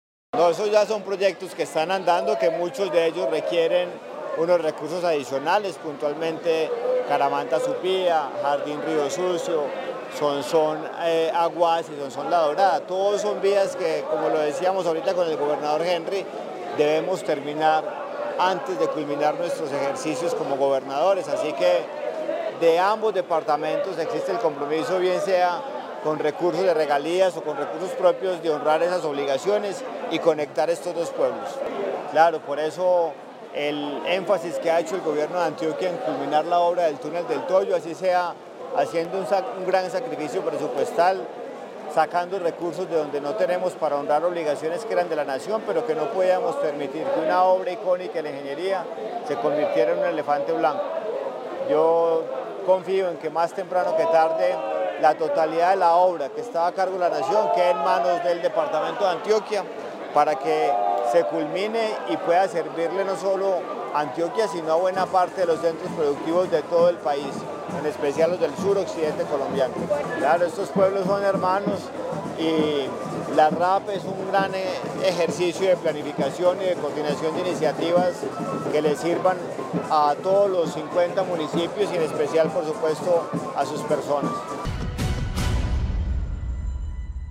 Andrés Julián Rendón, gobernador de Antioquia